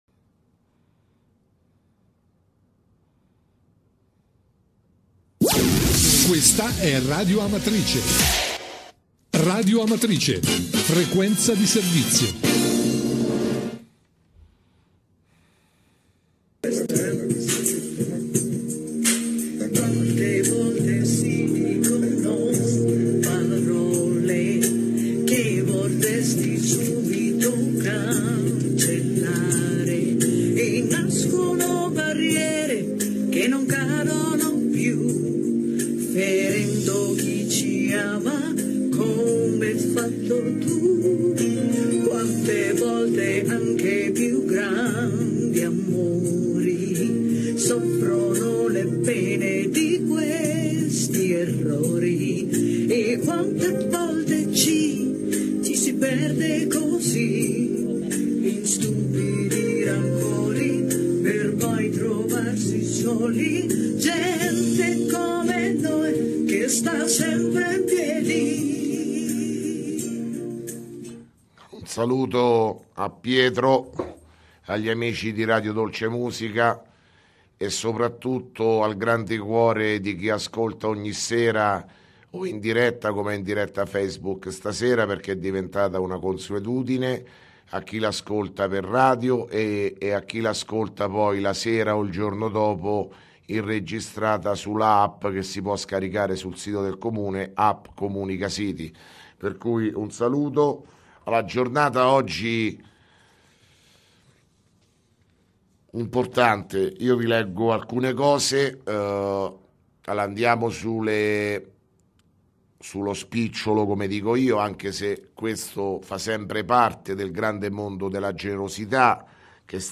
Di seguito il messaggio audio del Sindaco Sergio Pirozzi, del 7 marzo 2017